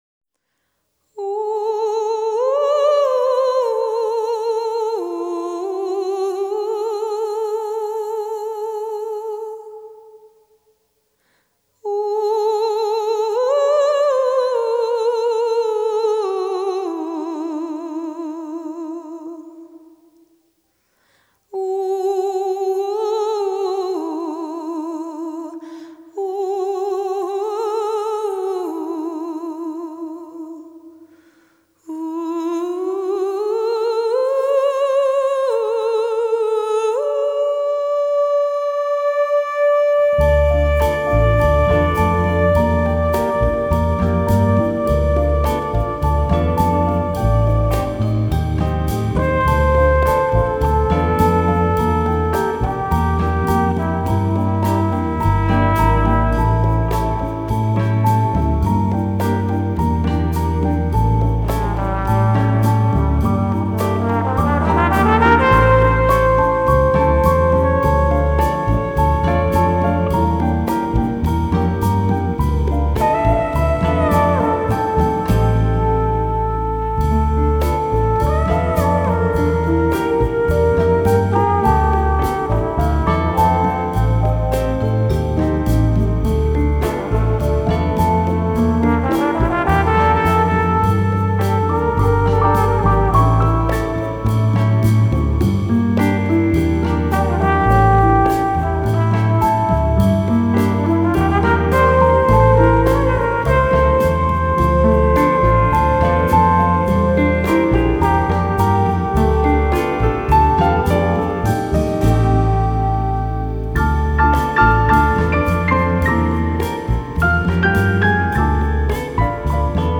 Genre: Jazz
在前奏就可以聞到濃濃的自然原始風味
Recorded at Stiles Recording Studio in Portland, Oregon.